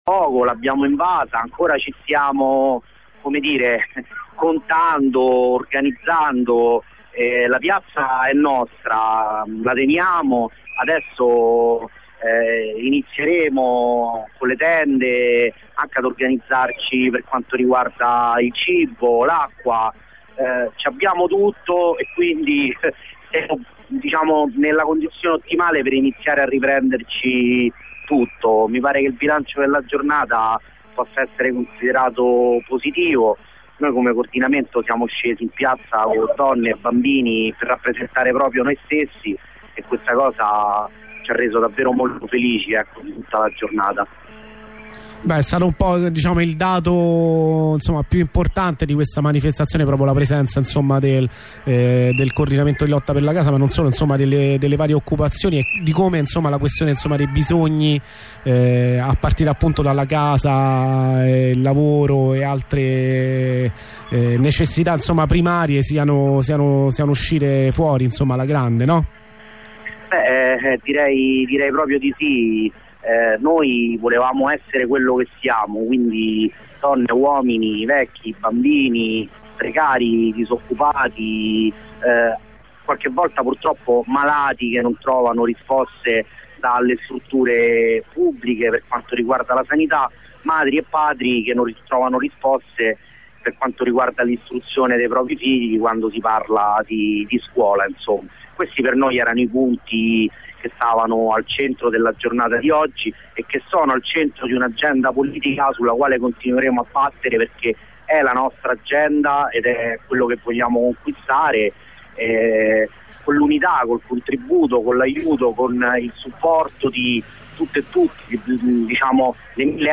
ore 18.50 Mentre nella piazza antistante Porta Pia ci si organizza per restare, prime valutazioni con un compagno del coordinamento cittadino di lotta per la casa. ore 19.24 una corrispondenza con un compagno della redazione. Si confermano 6 fermi tra i manifestanti.